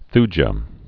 (thjə, thy-)